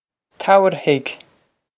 Tabharfaidh Tow-er-hig
This is an approximate phonetic pronunciation of the phrase.